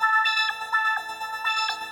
SaS_MovingPad04_125-A.wav